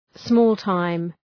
{,smɔ:l’taım}